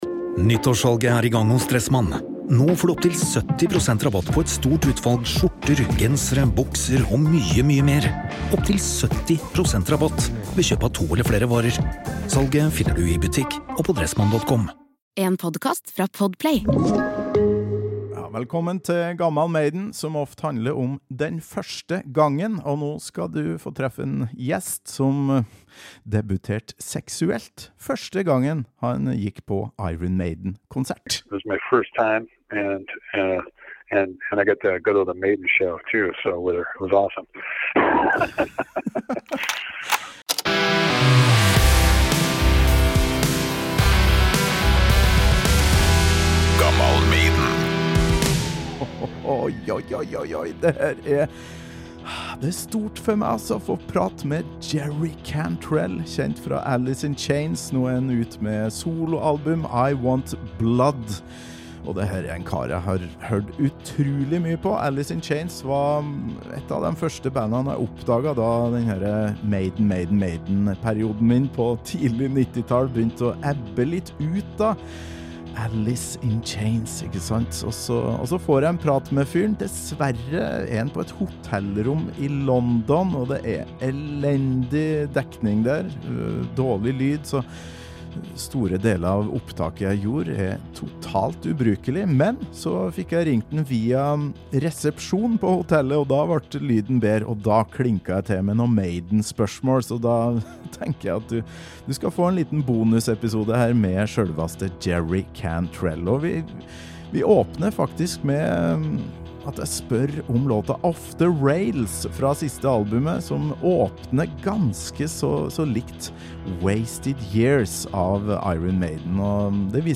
Dessverre var telefonlyden totalt ubrukelig i første halvdel av intervjuet. Etter hvert ble det bedre og det er altså den siste delen du får høre her.